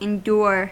Ääntäminen
US : IPA : /ɪnˈd(j)ʊɹ/